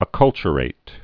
(ə-kŭlchə-rāt)